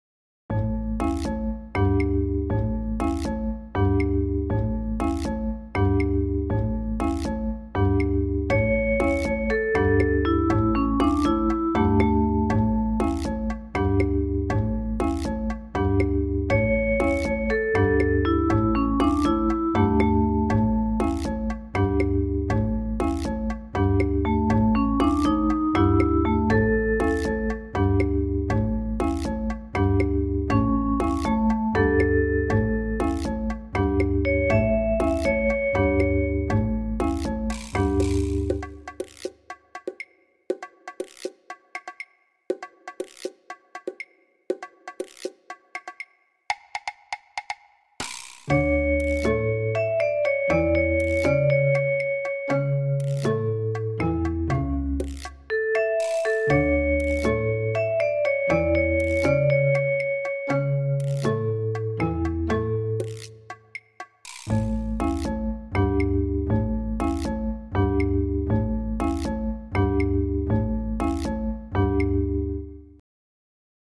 • 雰囲気：わくわく／南国感
楽しそうな曲 (222 ダウンロード )